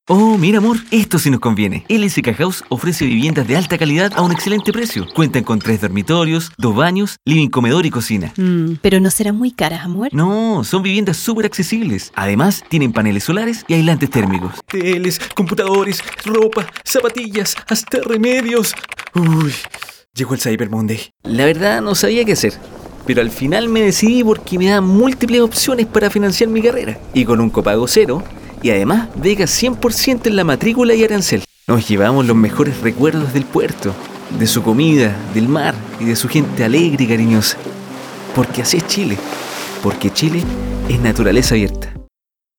También Cálida, Creíble, Fresca, Enérgica y Reconocible.
chilenisch
Sprechprobe: Industrie (Muttersprache):
Also, Warm, Believable, Fresh, Energetic and Relatable.